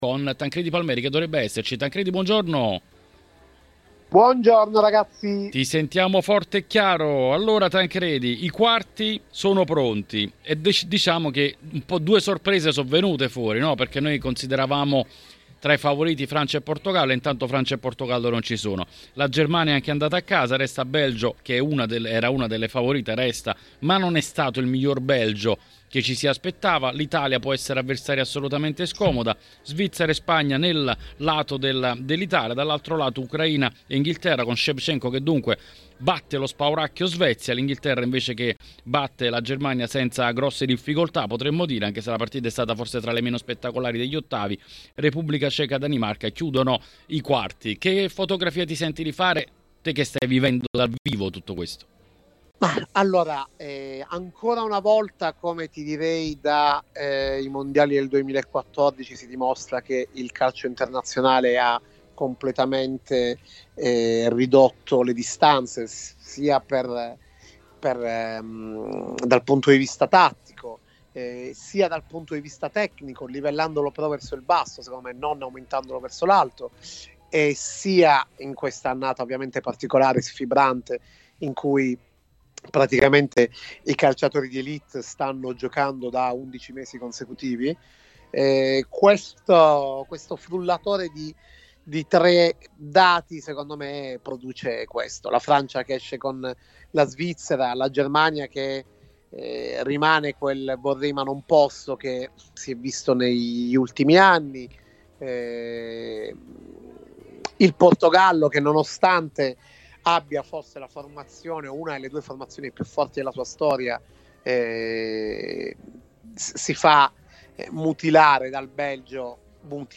nel consueto editoriale del mercoledì di TMW Radio